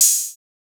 TS OpenHat_2.wav